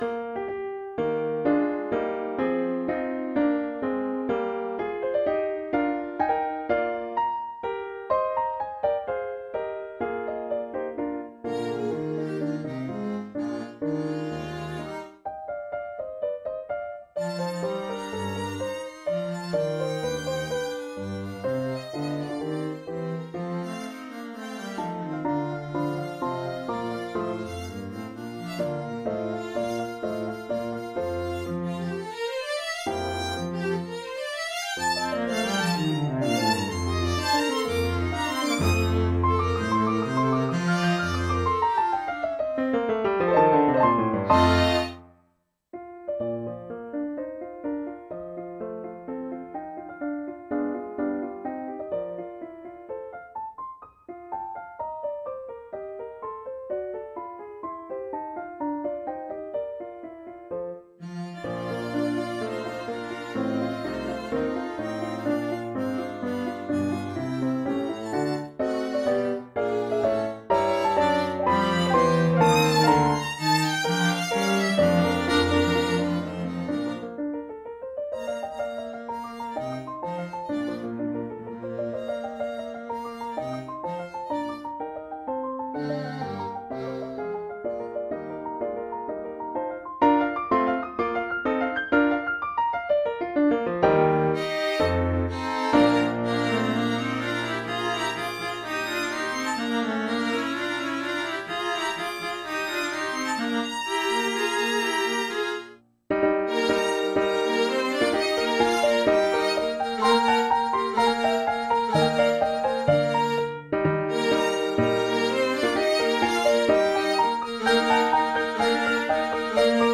The second movement us an elegant Rondo, moderato